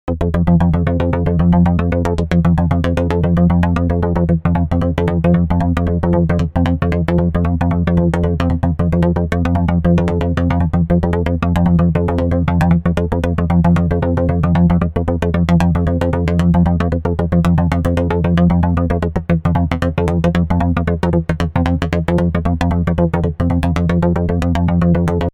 The instruments are controlled by MBSEQV4L itself, drums have been added on some samples, they are played by an external drum machine and synchronized via MIDI clock to MBSEQV4L.
Groove Styles: different groove styles are selected after the first 4 bars. A groove style varies the velocity, note delay and note length. Extreme settings have been used so that the effect can be noticed.
mbseqv4l_demo_groove1.mp3